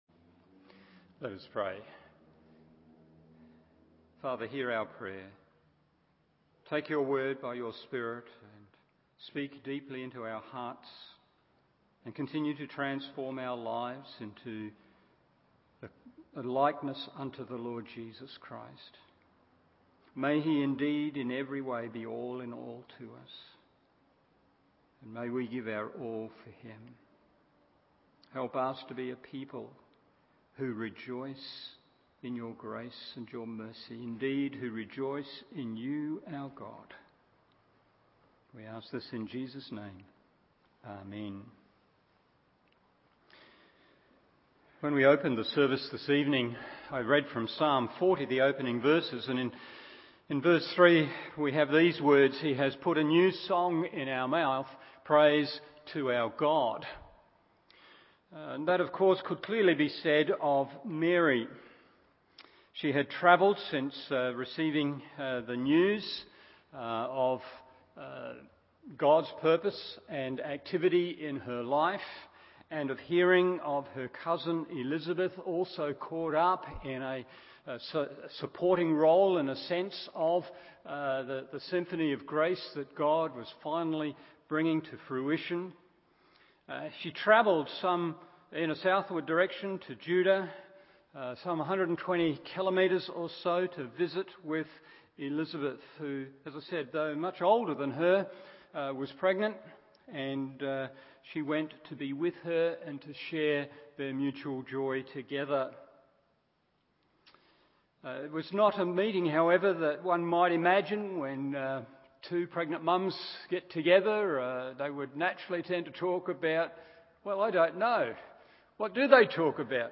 Evening Service Luke 1:46-55 1. It’s Passion 2. It’s Perception 3.